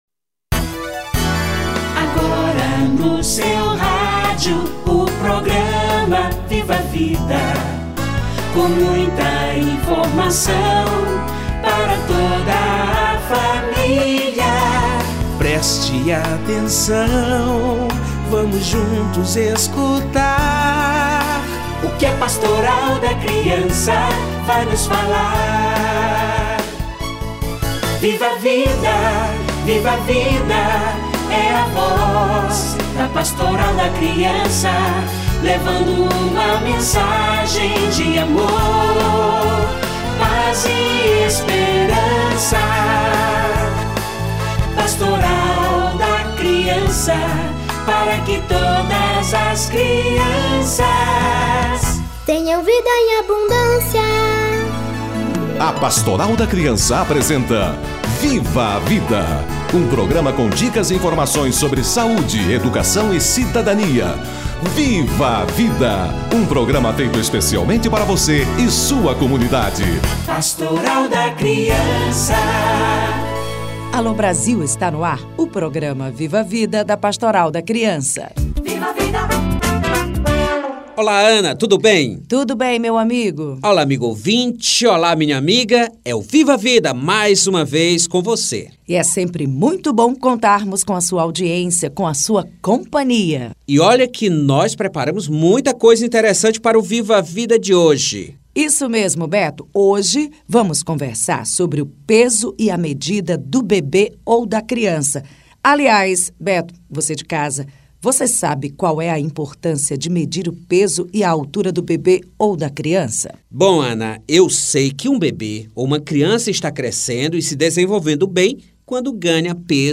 Peso e medida da criança - Entrevista